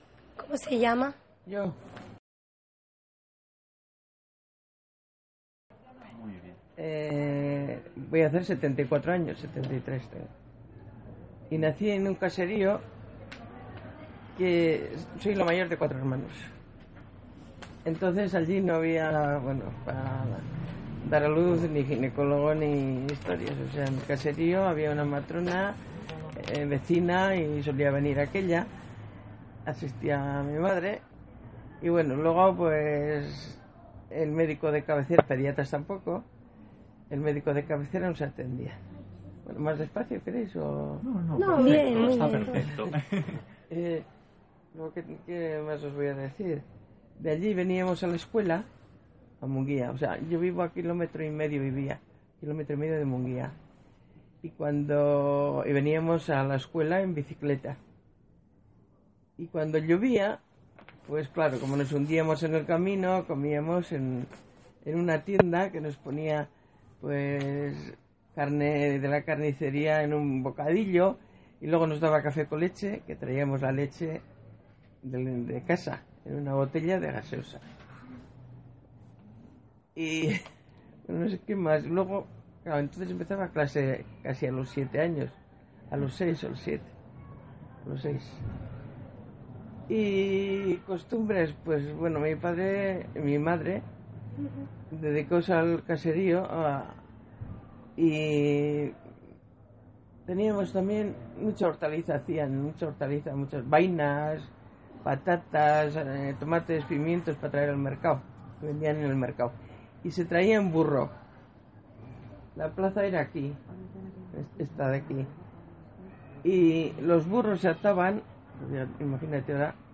Locality Mungia